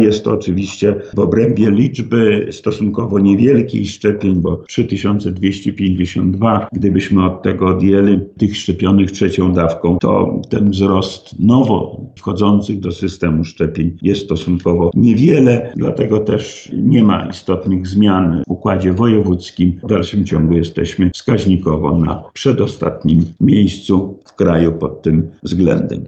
– Wskaźnikowo jesteśmy 3 listopada na pierwszym miejscu, jeżeli chodzi o wyszczepialność na 10 tysięcy mieszkańców z wynikiem 15,36 – mówi wojewoda lubelski Lech Sprawka.